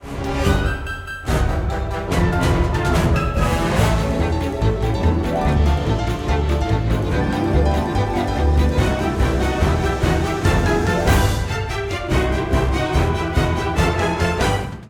Music[edit]
arrangements